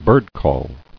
[bird·call]